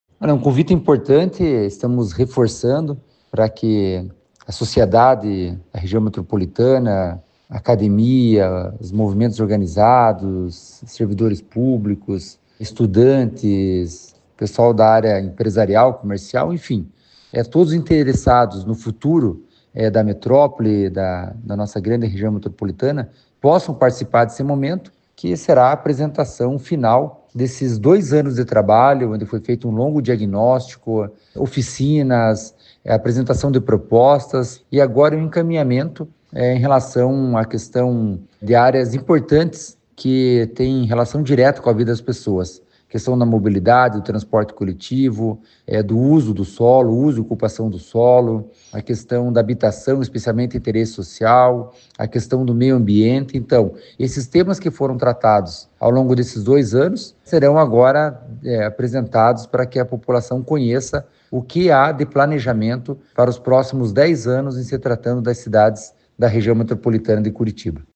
Sonora do diretor-presidente da Amep, Gilson Santos, sobre a última Audiência Pública do Plano de Desenvolvimento Urbano Integrado da Região Metropolitana de Curitiba